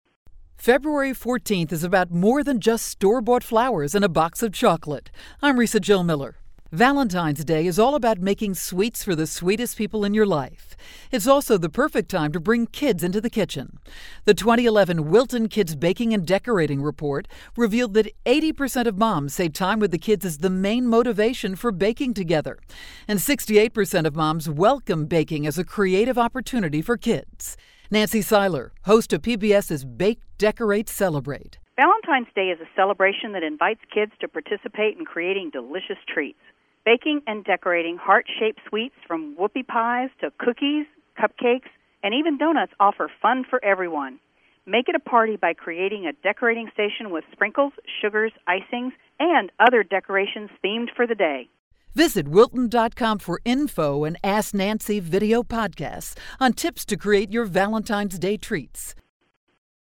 February 7, 2012Posted in: Audio News Release